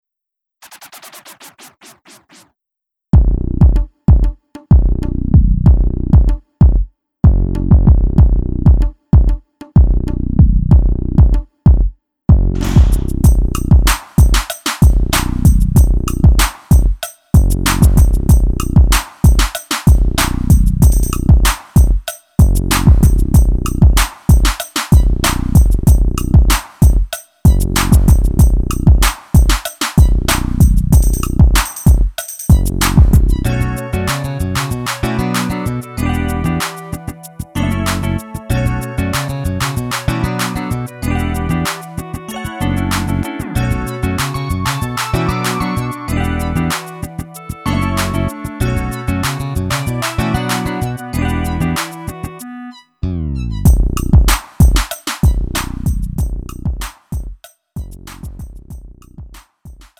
음정 -1키 3:15
장르 구분 Lite MR